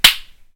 clap.ogg